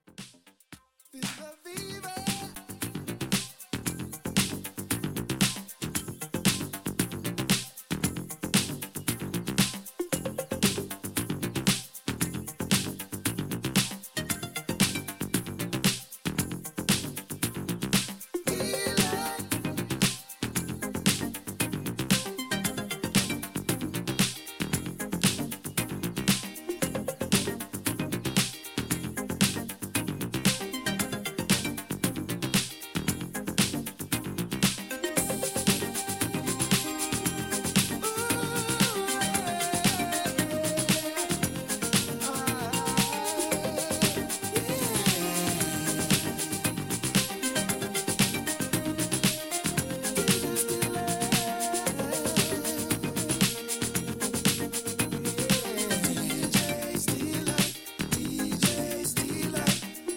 a pair of funk covers